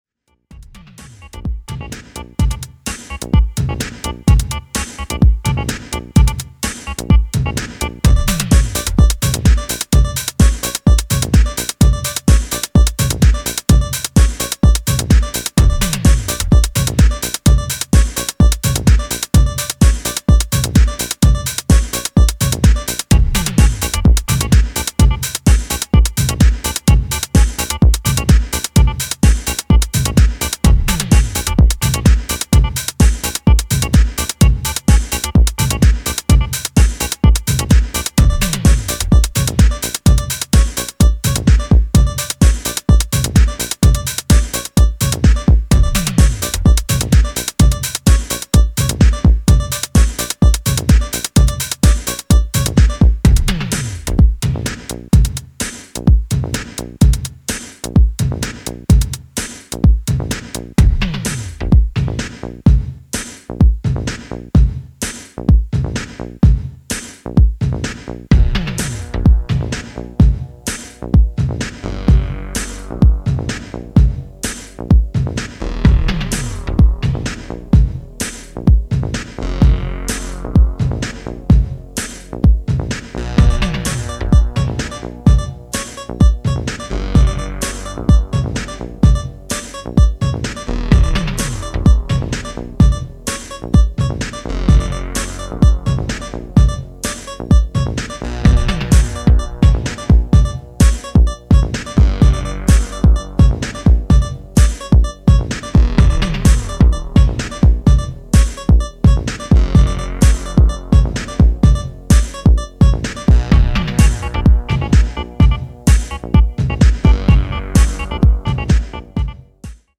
キレの良いポリシンセに狂うマシン・ファンク